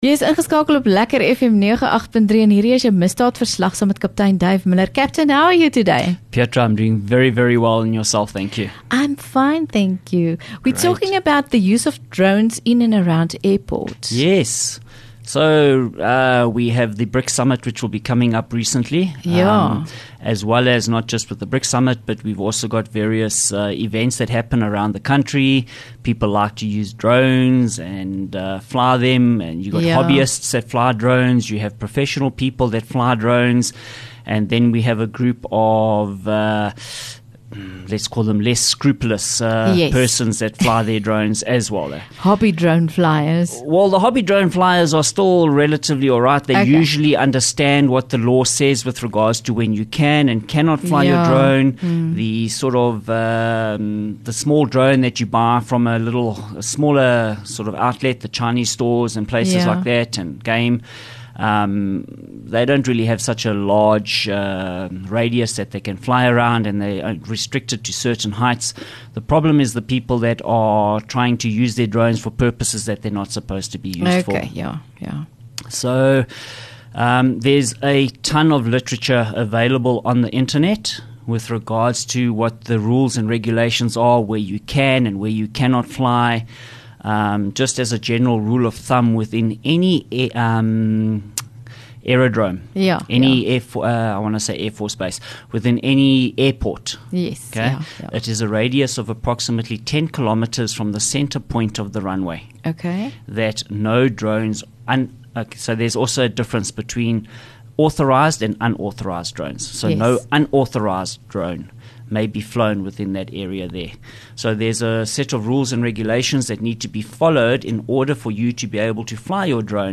LEKKER FM | Onderhoude 8 Aug Misdaadverslag